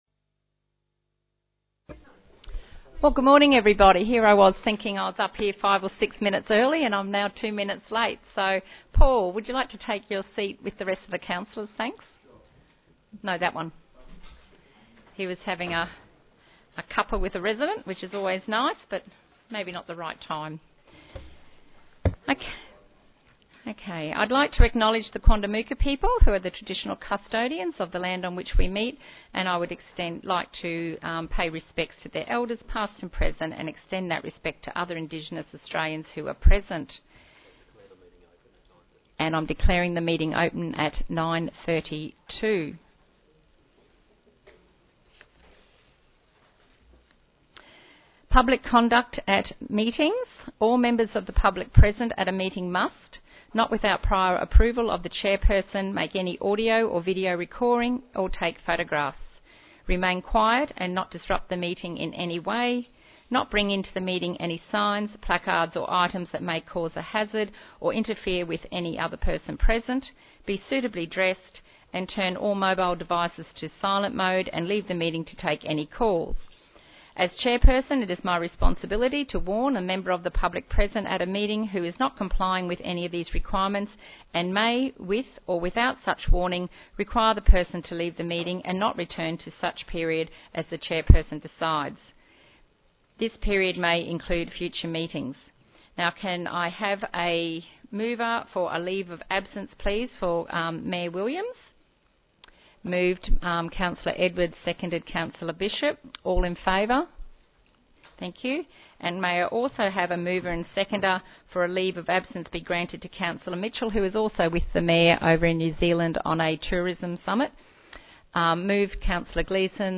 2018 Council meeting minutes and agendas